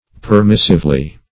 Search Result for " permissively" : Wordnet 3.0 ADVERB (1) 1. in a permissive manner ; The Collaborative International Dictionary of English v.0.48: Permissively \Per*mis"sive*ly\, adv.